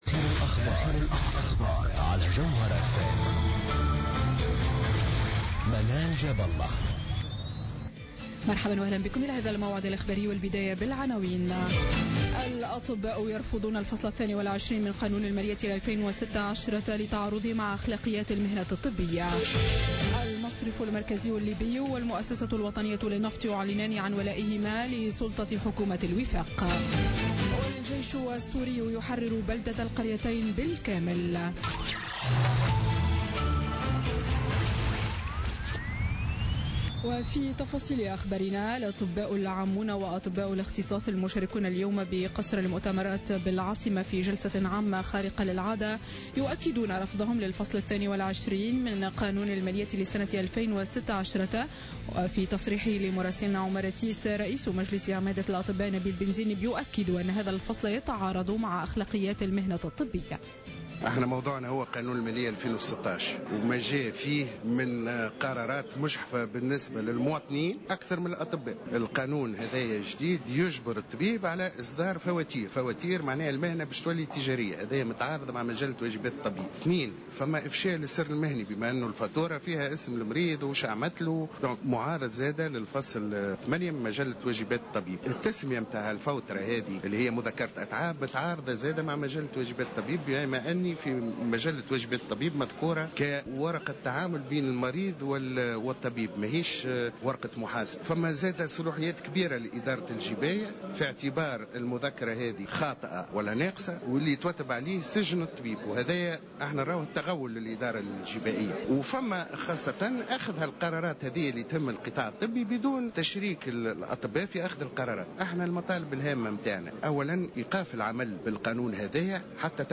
نشرة أخبار السابعة مساء ليوم الأحد 3 أفريل 2016